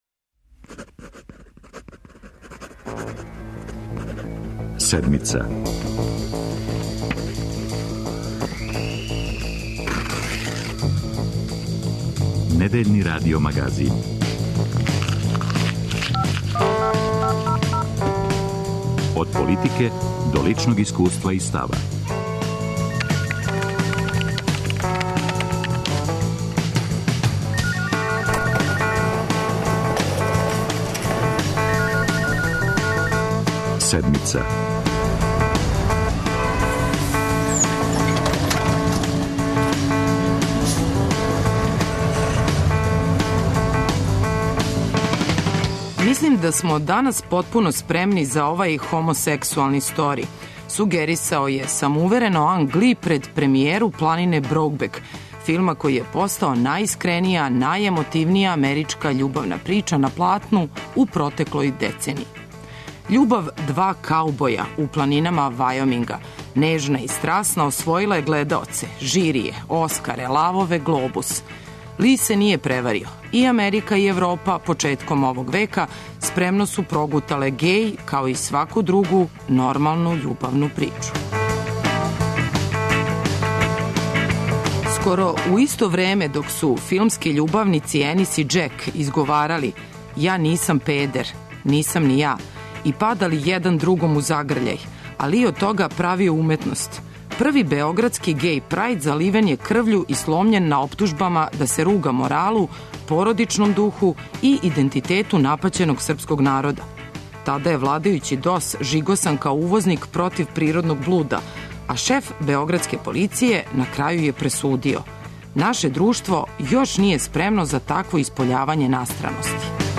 Надлежни у МУП-у и репортери Радио Београда 1 укључиваће се током емисије у програм.